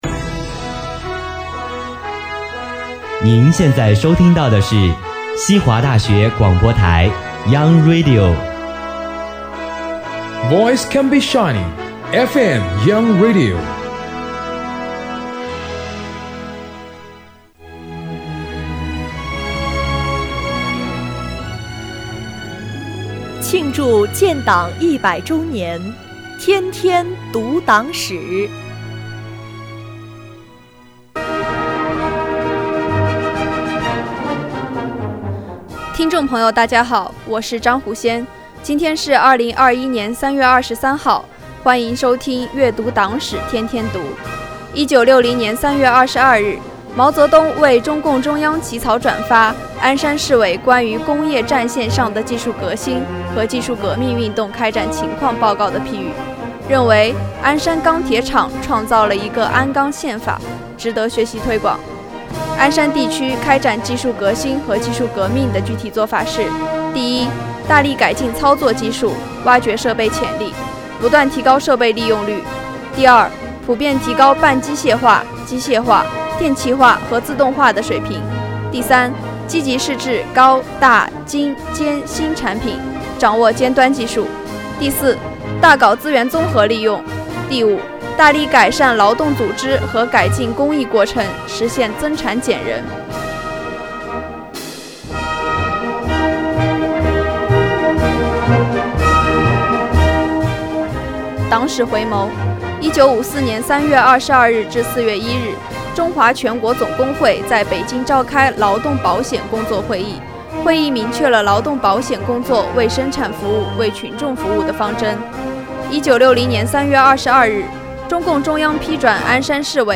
西华学子悦读党史